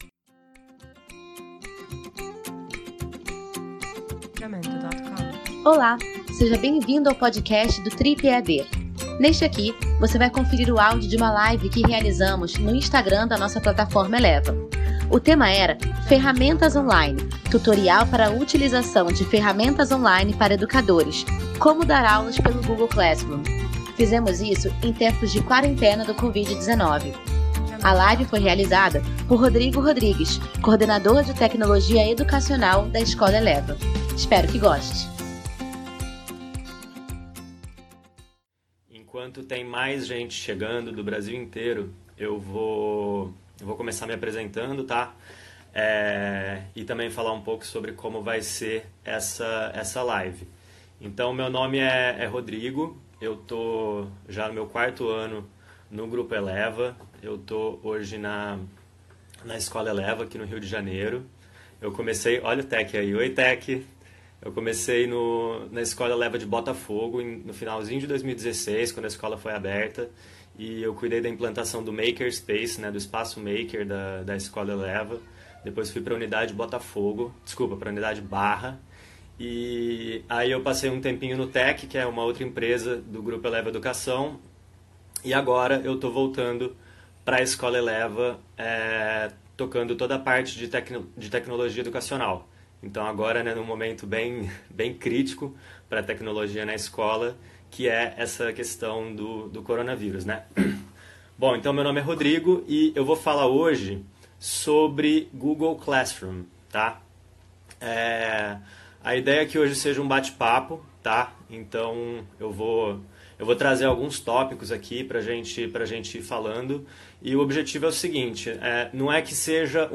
Nesta série “Viva Eleva”, trazemos o áudio das lives divulgadas no perfil da Plataforma de Ensino Eleva, no instagram.